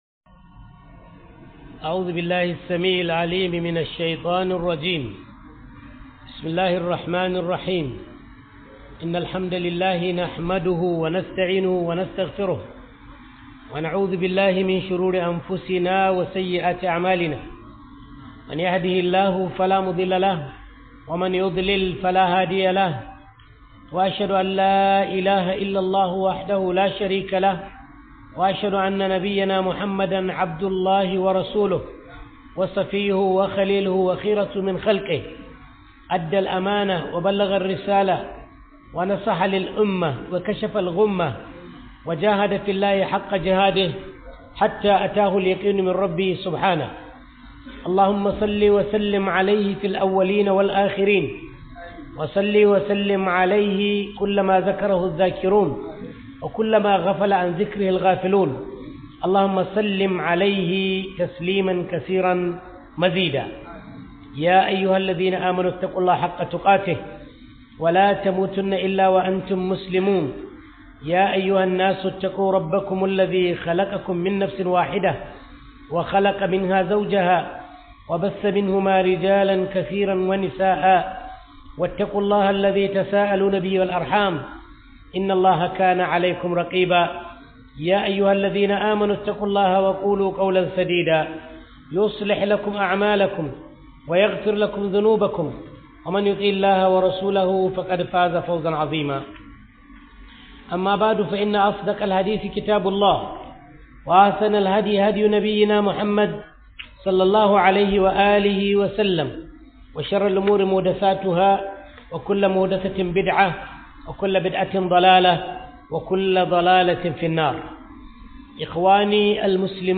Ramadan Tafseer 001